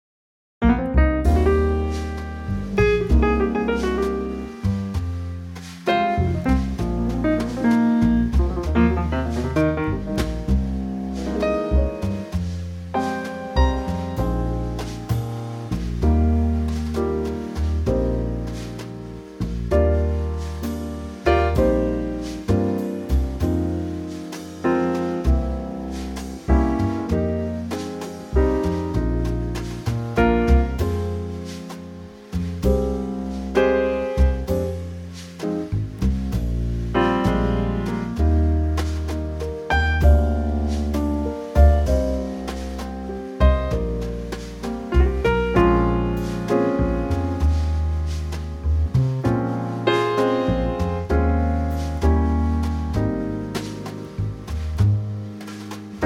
Unique Backing Tracks
key - Eb to F - vocal range - D to D
Trio arrangement
in a slow 4's blues feel.